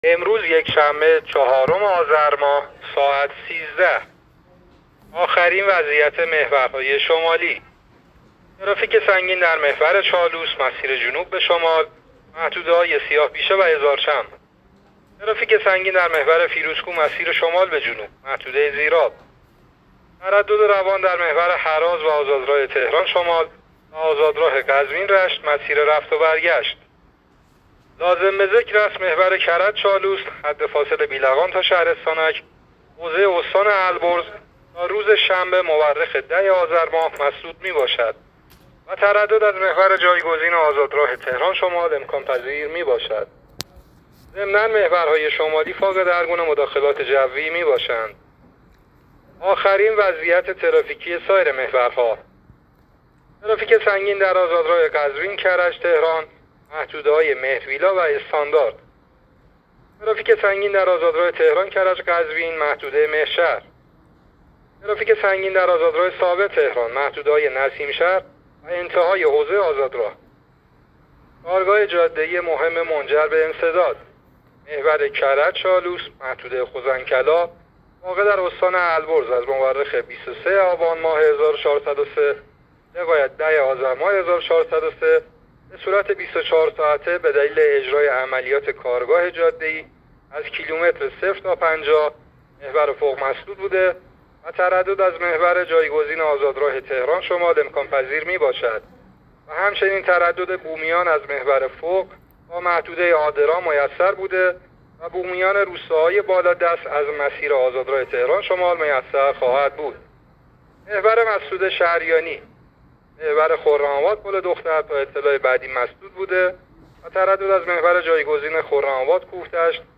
گزارش آخرین وضعیت ترافیکی جاده‌های کشور را از رادیو اینترنتی پایگاه خبری وزارت راه و شهرسازی بشنوید.
گزارش رادیو اینترنتی از آخرین وضعیت ترافیکی جاده‌ها تا ساعت ۱۳ چهارم آذر؛